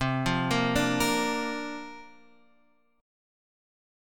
C9sus4 chord